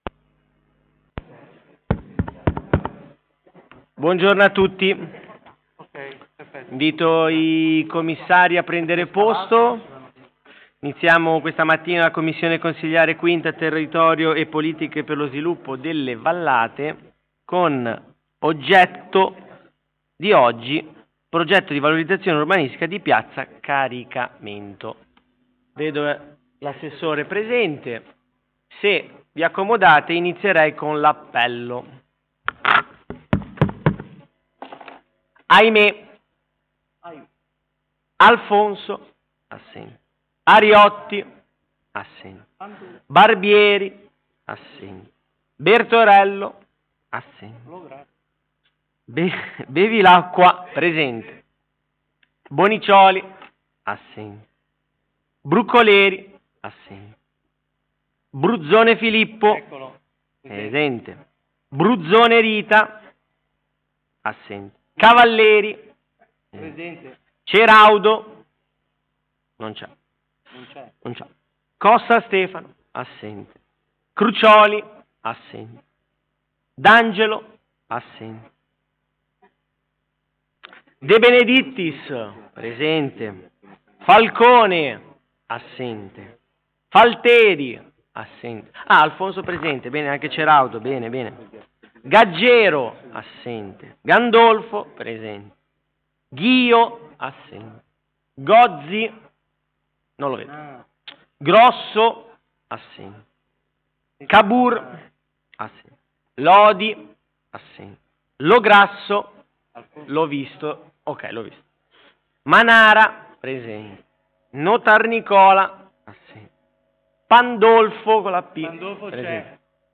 Commissione consiliare o Consiglio Comunale: 5 - Territorio e Promozione delle Vallate
Luogo: Presso la Sala Consiliare di Palazzo Tursi - Albini Ordine del giorno Progetto di valorizzazione urbanistica di Piazza Caricamento.